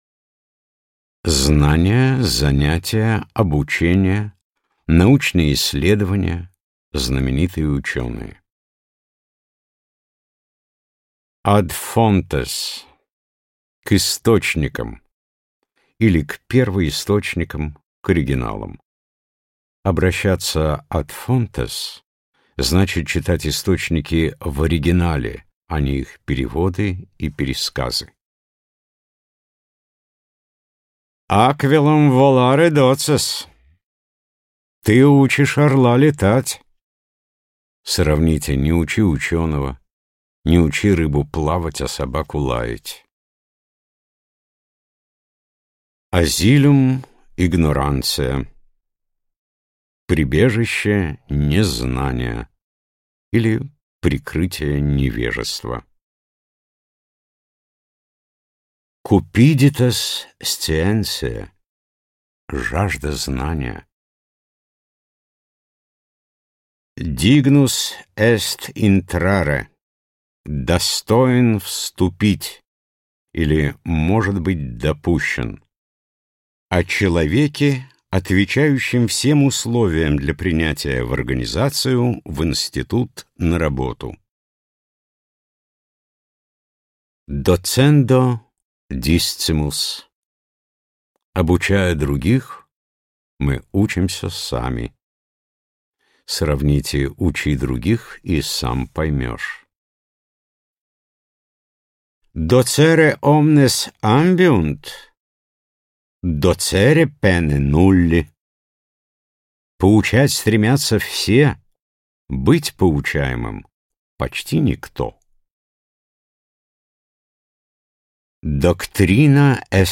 Аудиокнига Блеснем латынью: Крылатые фразы по-латыни и по-русски | Библиотека аудиокниг
Прослушать и бесплатно скачать фрагмент аудиокниги